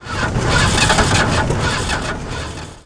Rumbling Sound
轰隆轰隆